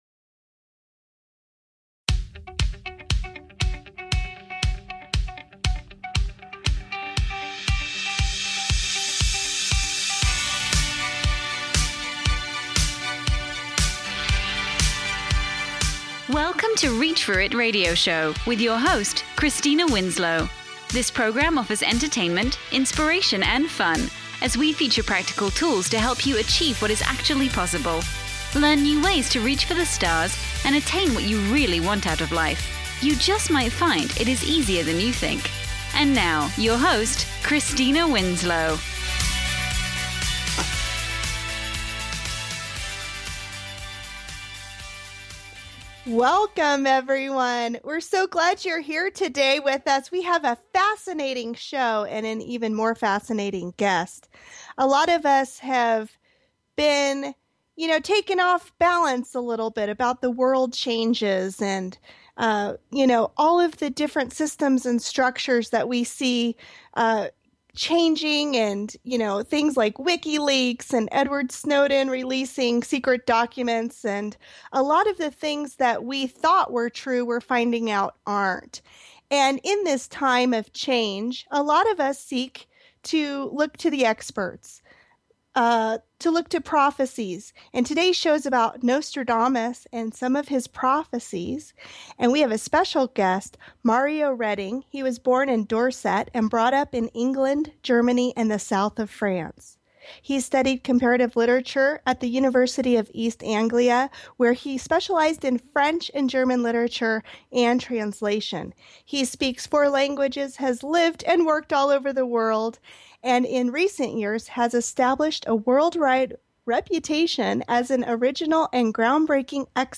Talk Show Episode, Audio Podcast, Reach For It Radio Show and Nostradamus Future Prophesies on , show guests , about Nostradamus,2015,Predictions, categorized as History,Philosophy,Mystic & Seer,Divination